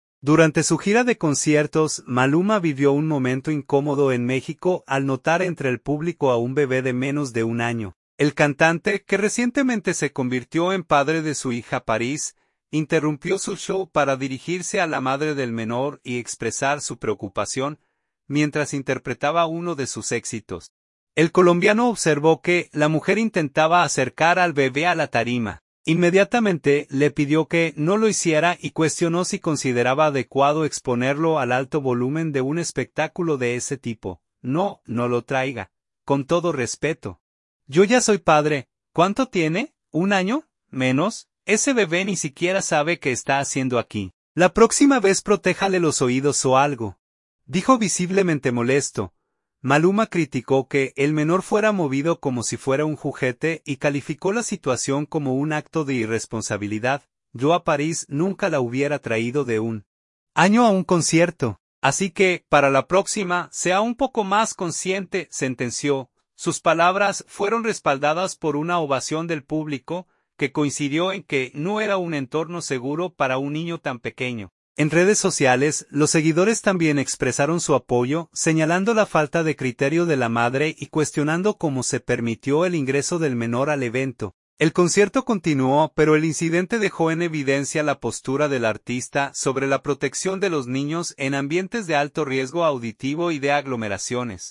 Mientras interpretaba uno de sus éxitos, el colombiano observó que la mujer intentaba acercar al bebé a la tarima.
“No, no lo traiga. Con todo respeto, yo ya soy padre. ¿Cuánto tiene? ¿Un año? ¿Menos? Ese bebé ni siquiera sabe qué está haciendo aquí. La próxima vez protéjale los oídos o algo”, dijo visiblemente molesto.
Sus palabras fueron respaldadas por una ovación del público, que coincidió en que no era un entorno seguro para un niño tan pequeño.